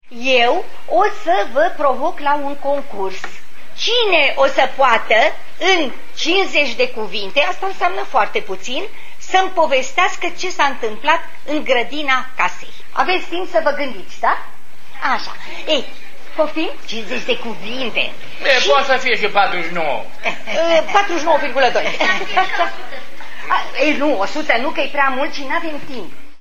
Sarbatoarea cartii pentru copii a umplut la refuz spatiul Galeriilor de Arta Arcadia unde prichindeii din Slobozia au ascultat povestioare spuse de autorii de basme.